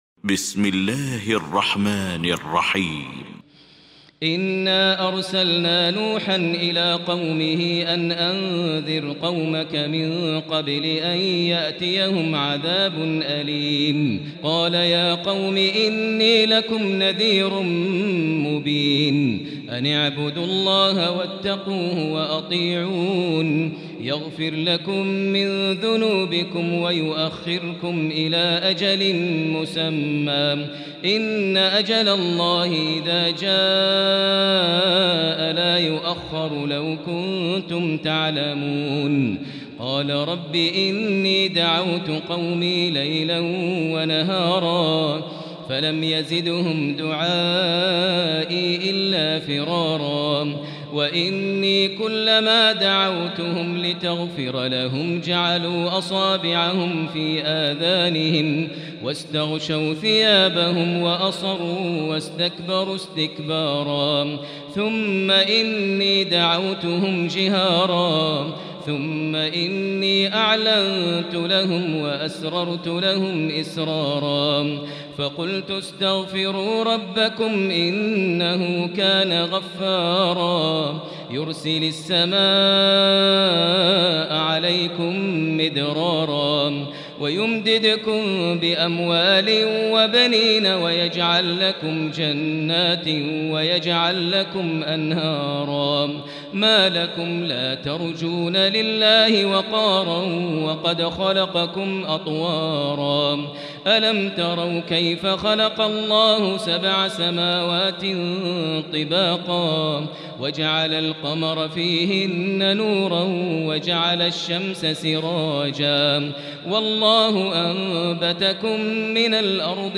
المكان: المسجد الحرام الشيخ: فضيلة الشيخ ماهر المعيقلي فضيلة الشيخ ماهر المعيقلي نوح